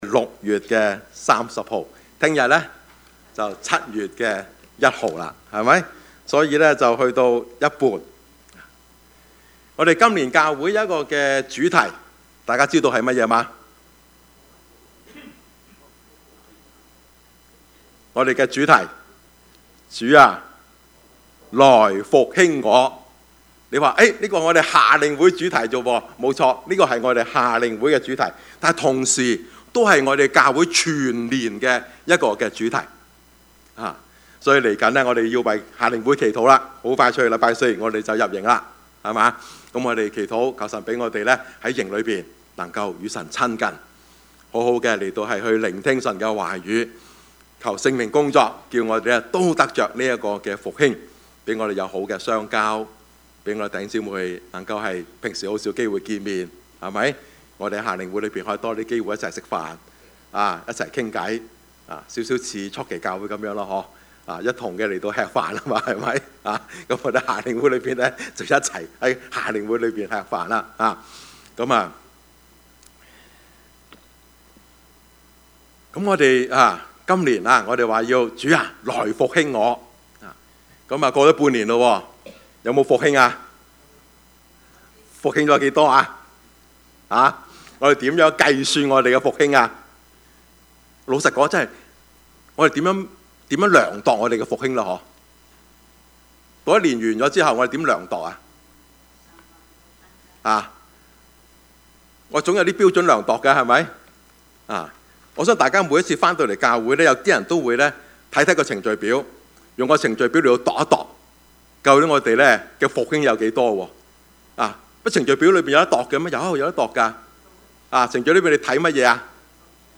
Service Type: 主日崇拜
Topics: 主日證道 « 同心合意分享 耶穌受試探 »